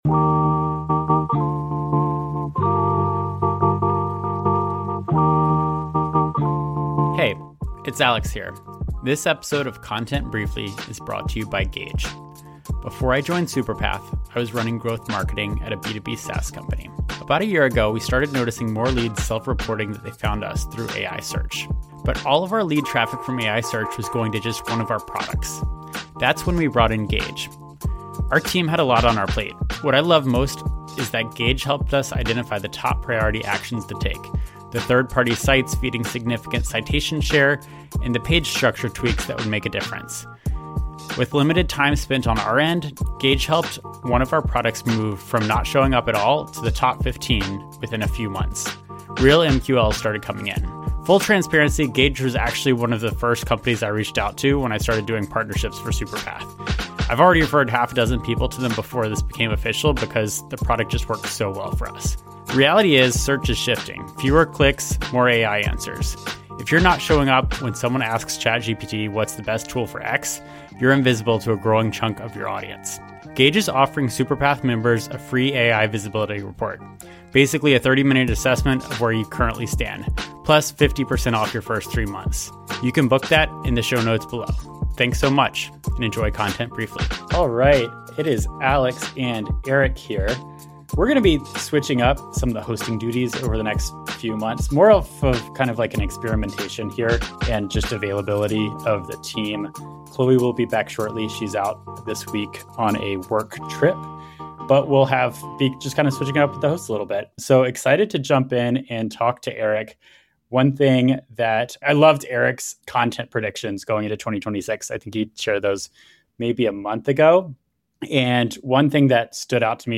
An optimistic, practical discussion for freelancers and in‑house marketers navigating the next era of content work.